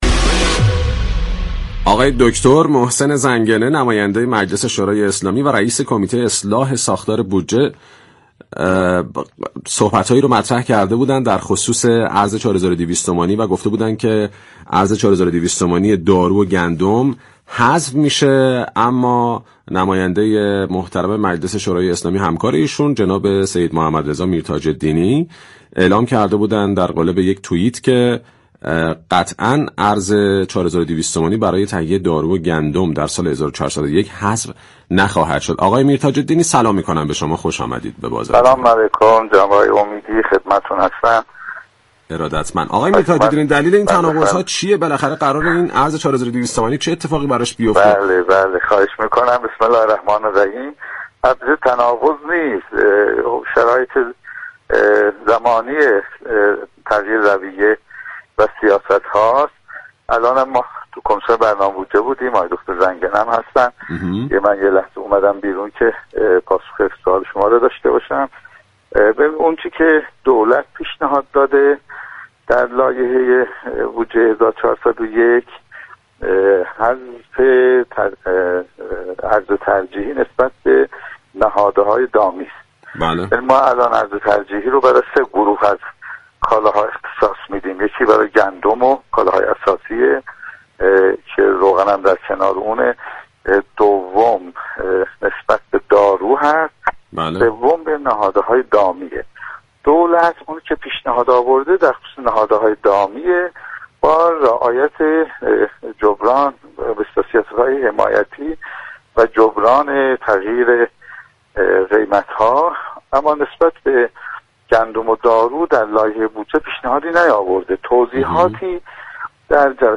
به گزارش پایگاه اطلاع رسانی رادیو تهران، سید محمدرضا میر‌تاج‌الدینی عضو كمیسیون برنامه و بودجه و محاسبات مجلس درباره حذف ارز ترجیحی در لایحه بودجه سال 1401 و حذف ارز ترجیحی تهیه دارو و گندم در گفتگو با بازار تهران رادیو تهران گفت: آنچه كه دولت در لایحه بودجه سال 1401 ارائه داده است حذف ارز ترجیحی نهاده‌های دامی است.